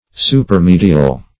Supermedial \Su`per*me"di*al\, a. Above the middle.